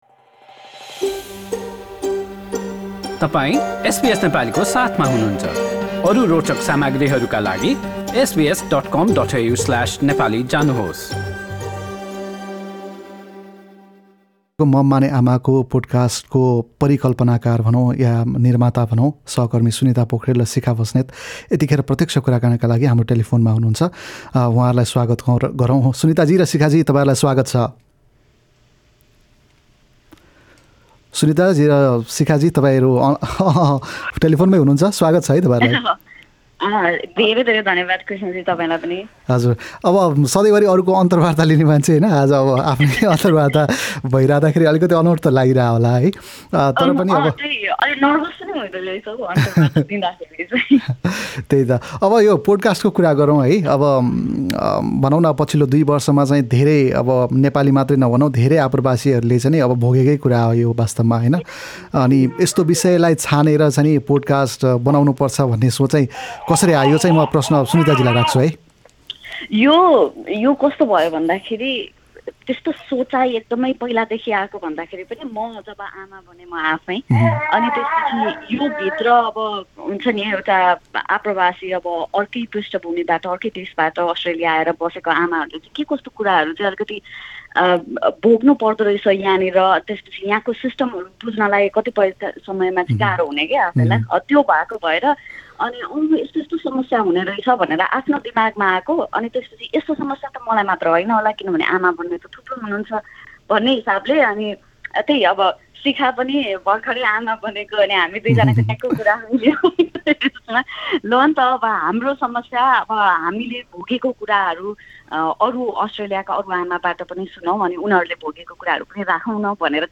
पोडकास्ट बारे गरिएको कुराकानी सुन्नुहोस्: Mum माने आमा: एसबीएसको नयाँ नेपाली पोडकास्ट श्रृङ्खला हाम्रा थप अडियो प्रस्तुतिहरू पोडकास्टका रूपमा उपलब्ध छन्।